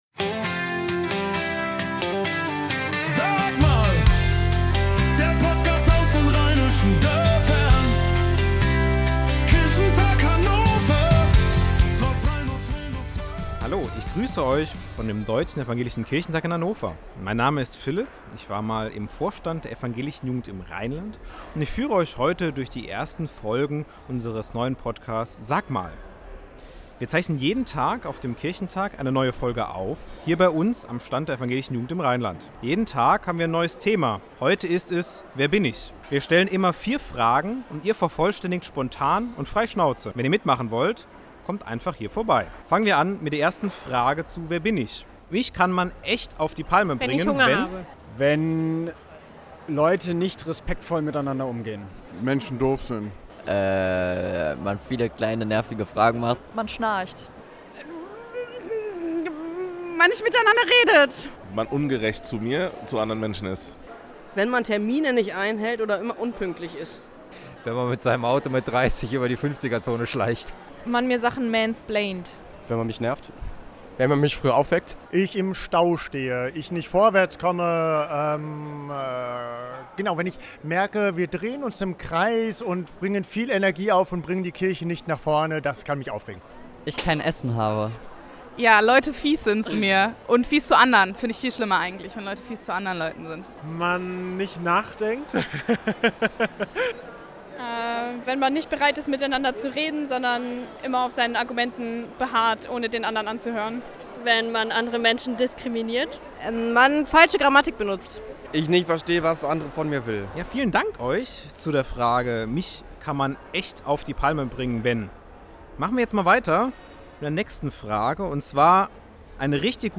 kurze Fragen in den Rheinischen Dörfern
Der Podcast aus den Rheinischen Dörfern der EjiR vom Kirchentag Hannover